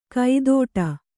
♪ kaidōṭa